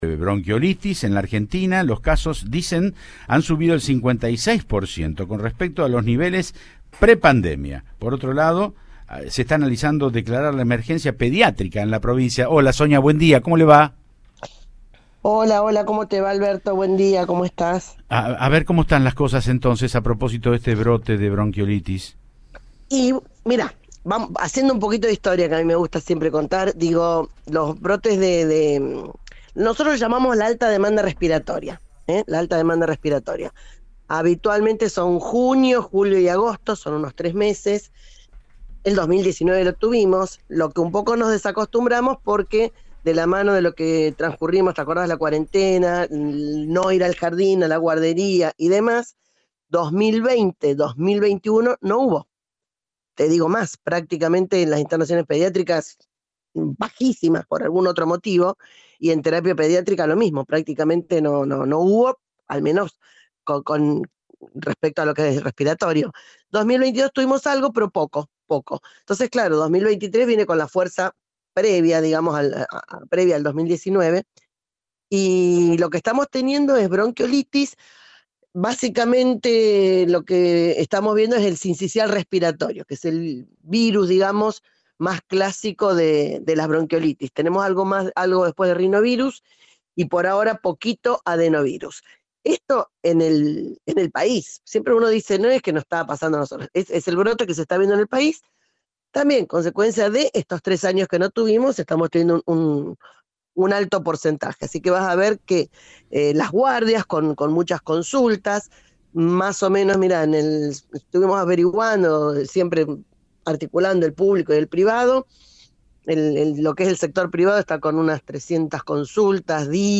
La Ministra de Salud, Sonia Martorano dialogó con Cadena 3 Rosario y brindó un panorama sobre la situación pediátrica que vive la provincia junto a la falta de médicos especialistas.
La Ministra de Salud de Santa Fe, Sonia Martorano habló sobre brote de bronquiolitis.